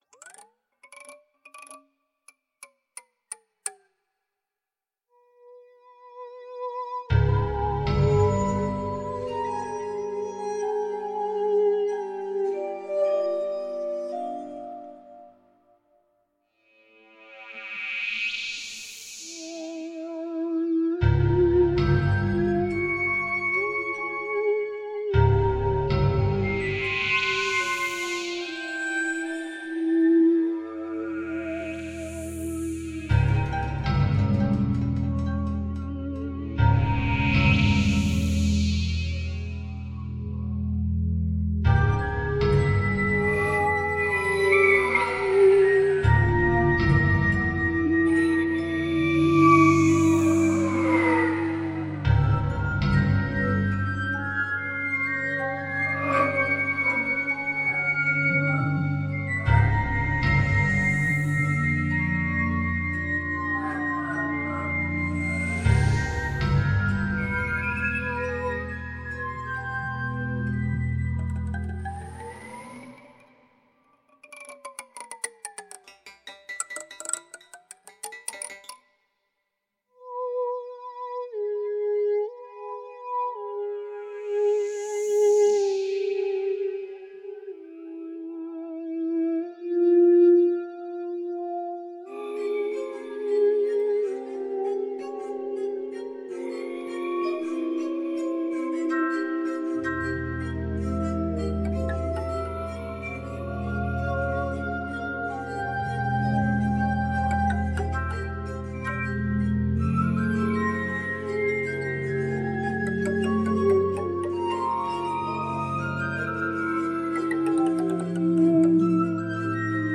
Comme le sculpteur, la musicienne soude ses matières en superposition : les ambiances bruitistes évoquent la peau de l’animal, ses écailles, ses piquants, sa rugosité. Puis la rythmique vient suggérer son mouvement et les torsions de sa musculature. Enfin la mélodie, découpée au chalumeau, traduit la voix de la bête, sa respiration intime et son message.